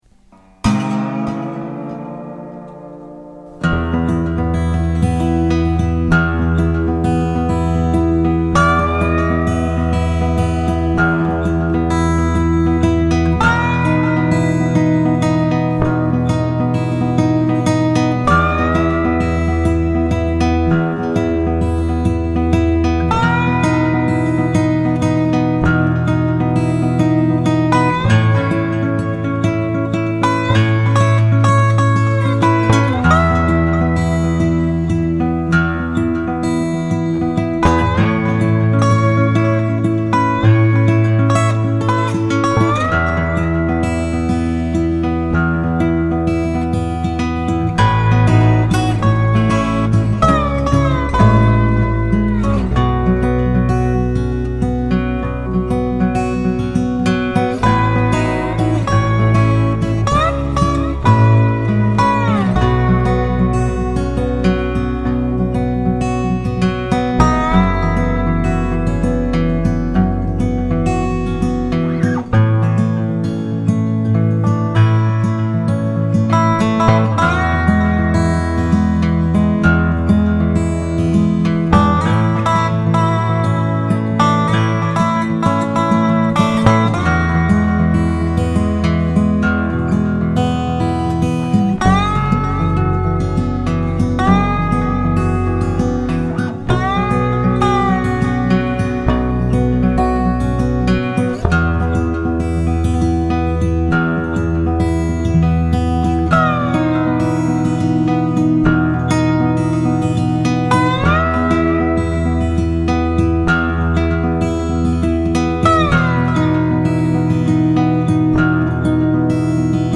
last night i added some instruments to a sketch and threw together this mix. i make no excuses about the poor intonation on the dobro.